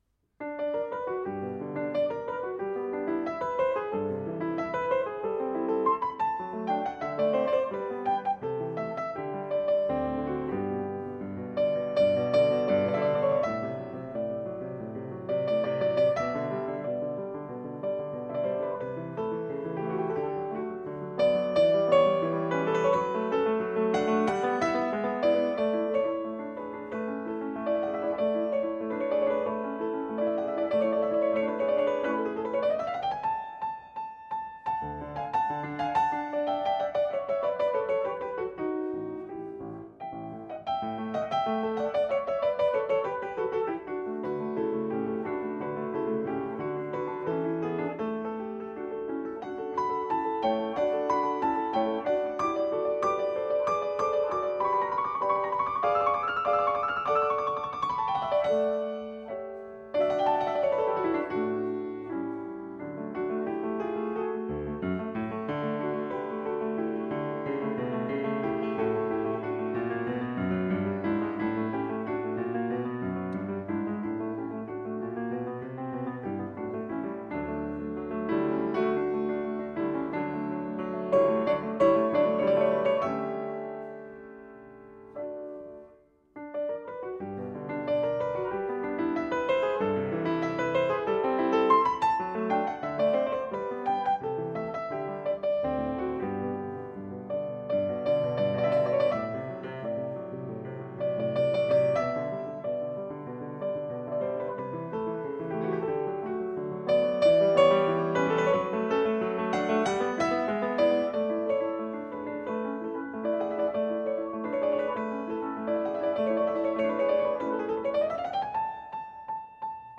Piano
solo piano
Style: Classical